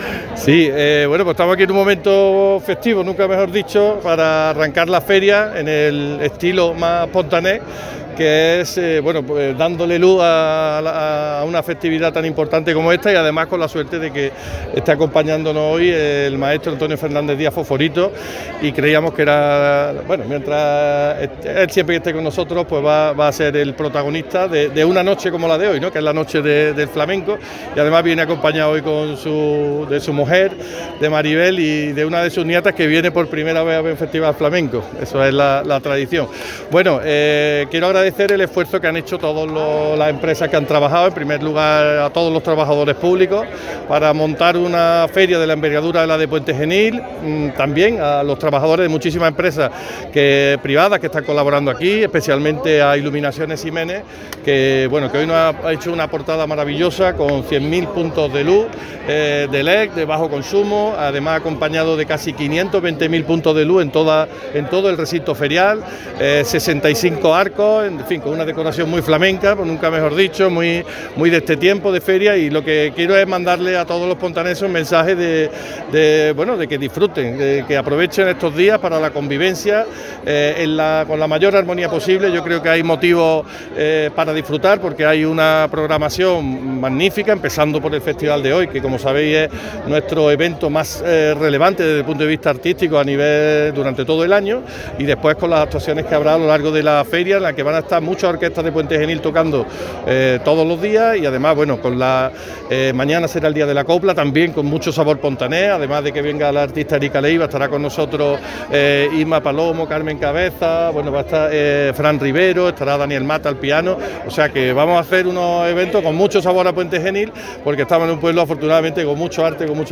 Declaraciones del alcalde
Declaraciones-Sergio-Velasco.-feria-2023.mp3